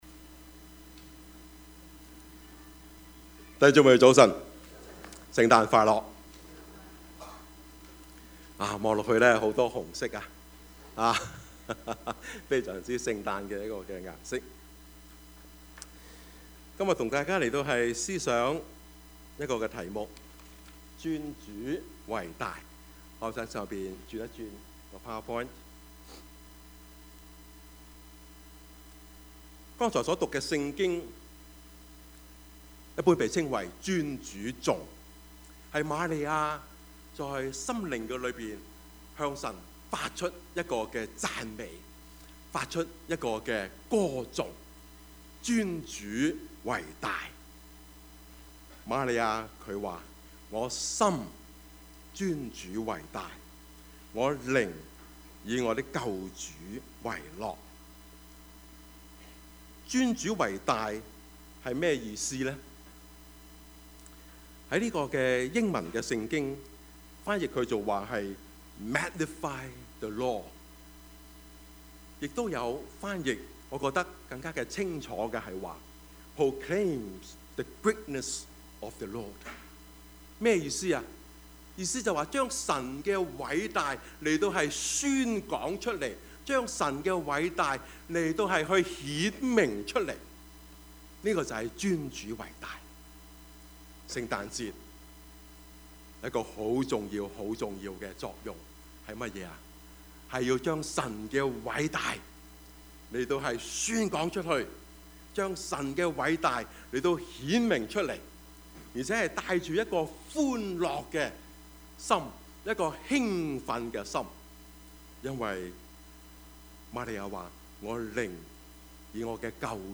Service Type: 主日崇拜
Topics: 主日證道 « 福音的起頭 耶穌的門徒是什麼樣子的？